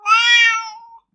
猫（来たよ〜）１c
cat1c.mp3